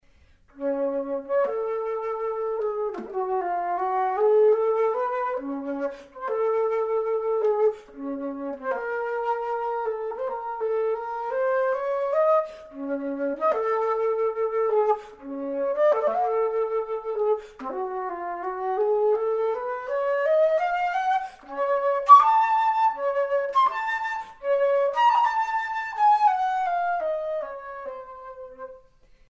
Alto Flute